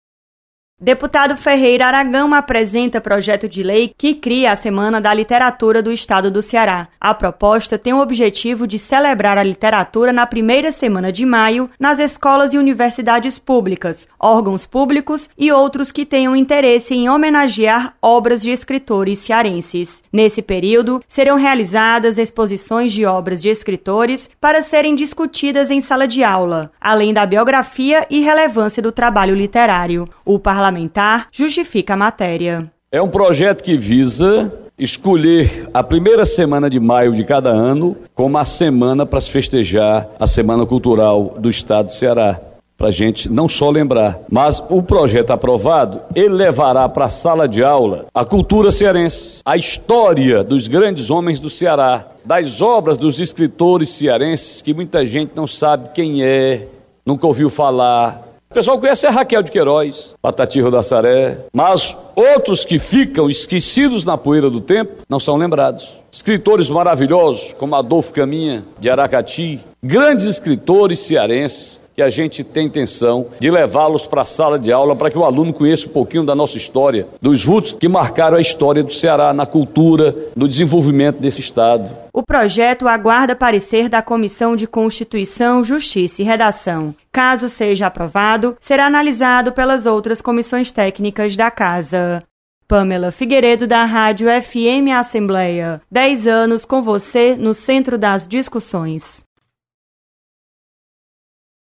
Projeto cria a Semana da Literatura do Estado do Ceará. Repórter